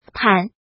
怎么读
pǎn
pan3.mp3